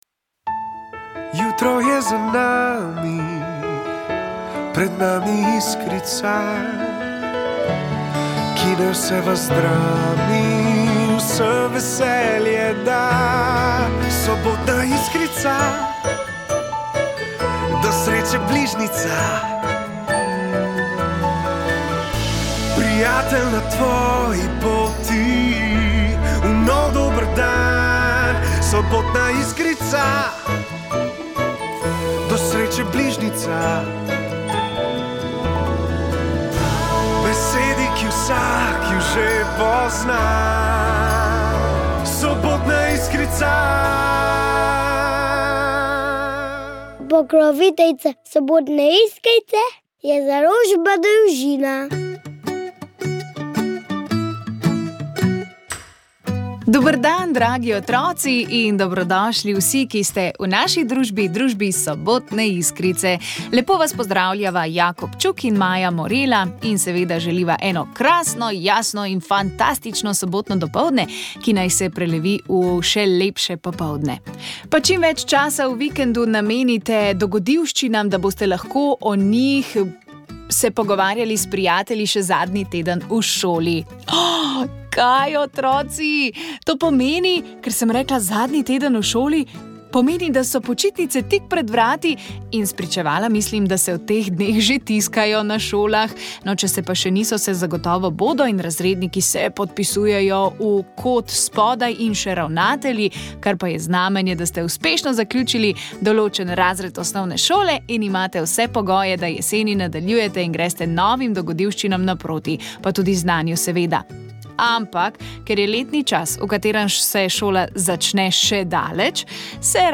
Napevi o Mariji so pletli tokratno oddajo iz cikla Pevci zapojte, godci zagodte.